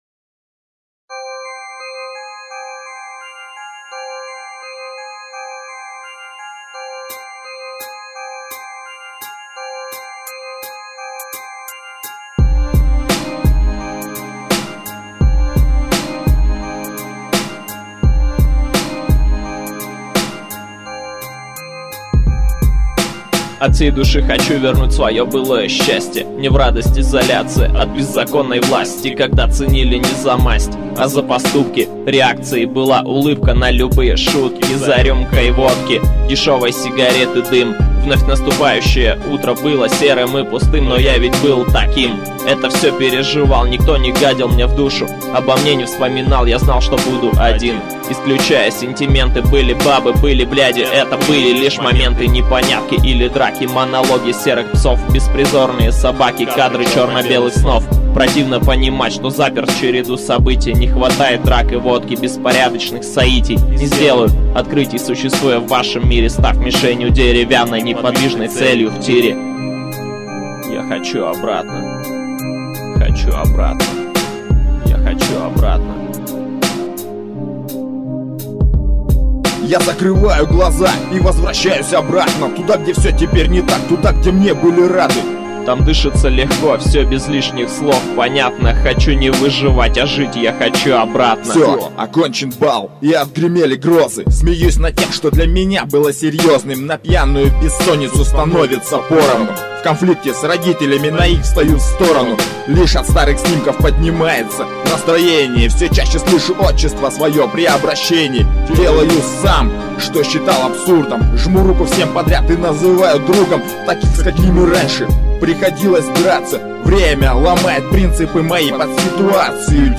undeground rap